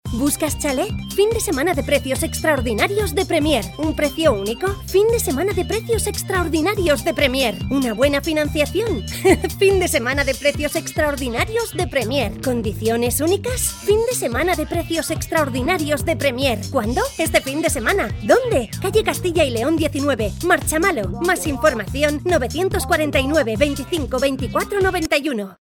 Locutores profesionales para la grabación de podcast.